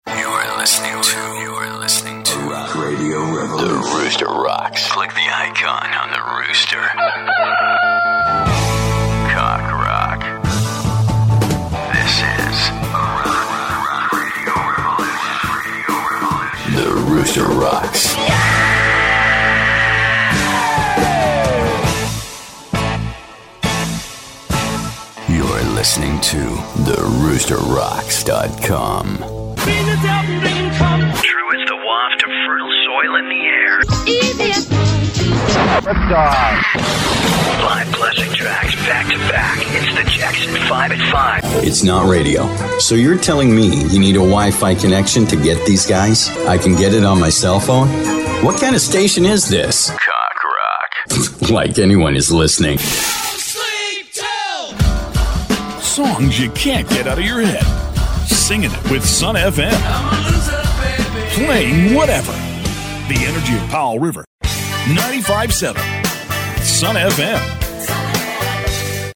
I'm a full-time Canadian male voice talent with my own professional home studio in Vancouver Canada.
Sprechprobe: Sonstiges (Muttersprache):
My vocal arsenal runs the gamut from the guy next door to wry to selected character and ethnic voices. I deliver a natural and believable read that engages the intended target audience and gets your product/service and brand the attention it deserves!